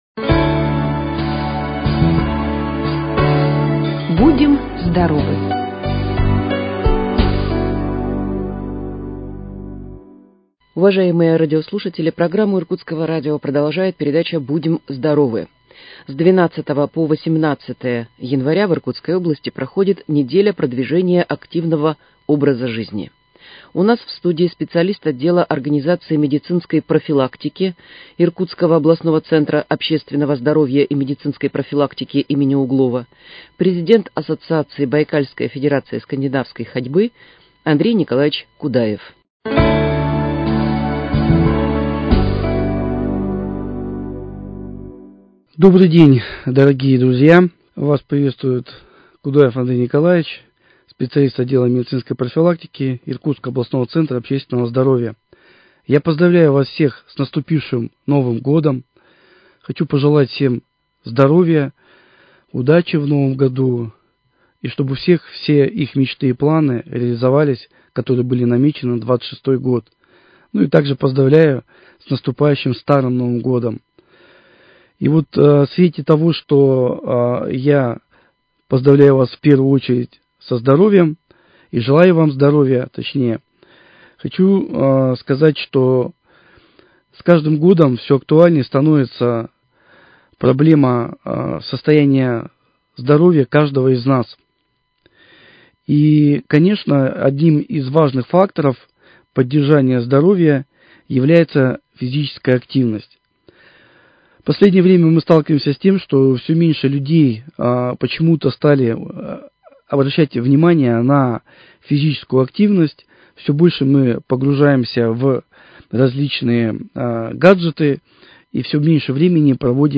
В нашей студии спикер